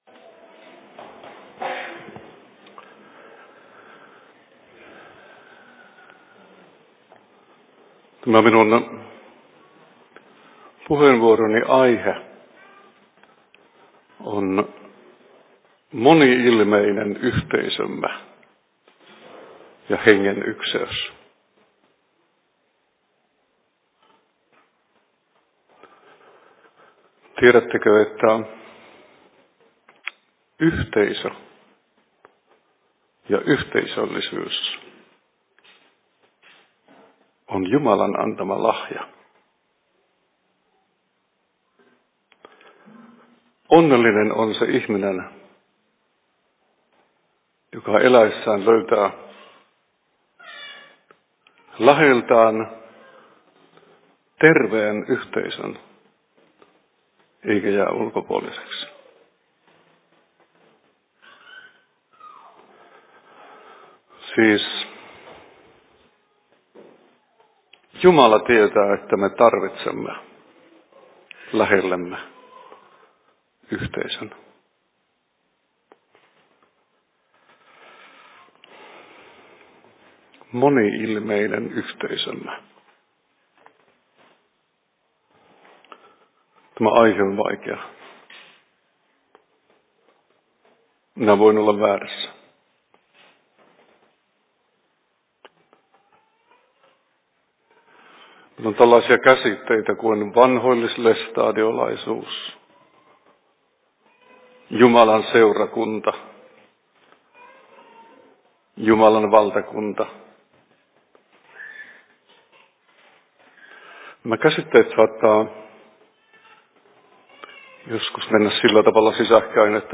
Paikka: Rauhanyhdistys Jyväskylä